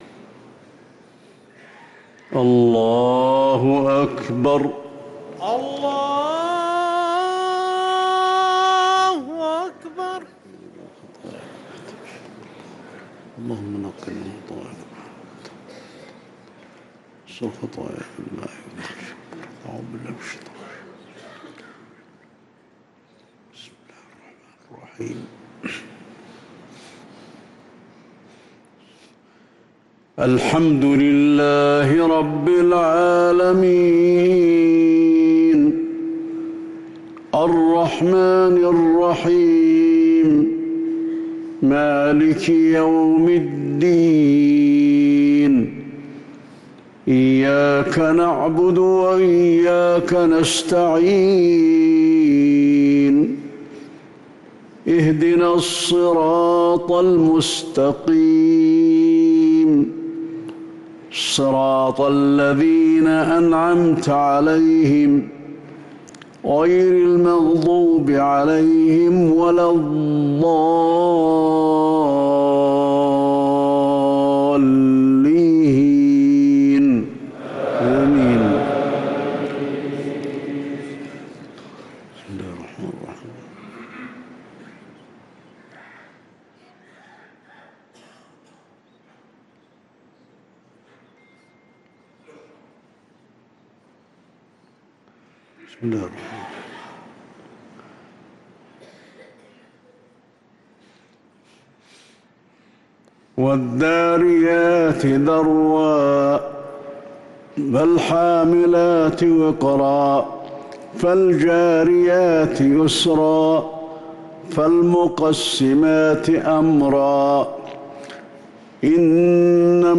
صلاة العشاء للقارئ علي الحذيفي 4 ربيع الآخر 1445 هـ
تِلَاوَات الْحَرَمَيْن .